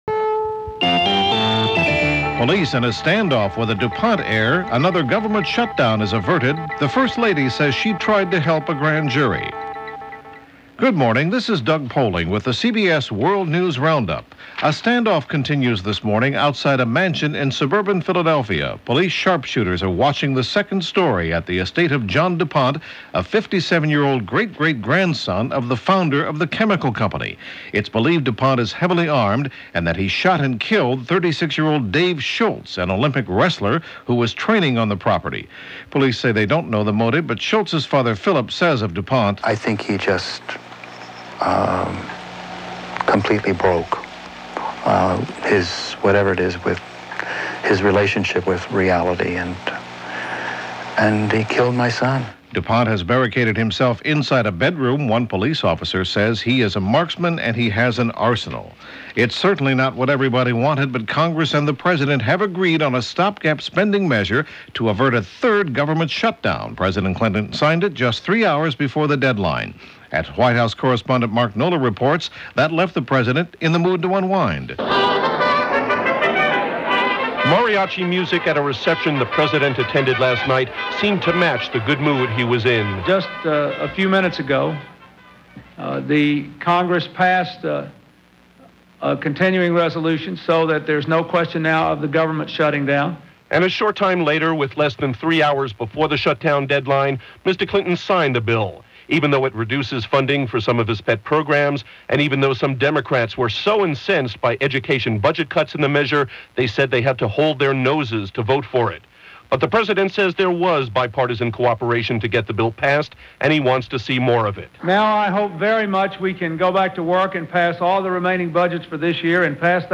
All that via The CBS World News Roundup for January 27, 1996 – a Saturday, of all things.